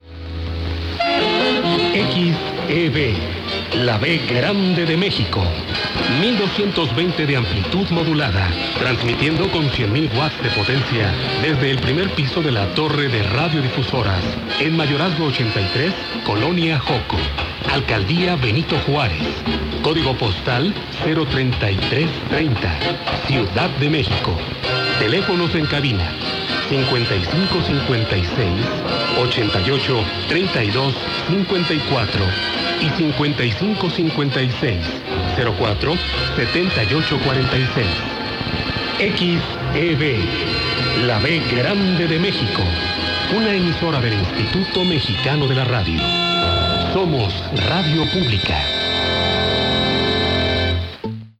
Indicativo de la estación XEB La B Grande de México.
Receptor: Kenwood R-600 Antena: Hilo largo de cobre de 10 metros de longitud por 6 metros de alto, con toma a tierra.
radioescucha-xeb-la-b-grande-de-mexico-1220-khz.mp3